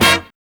4605R STAB.wav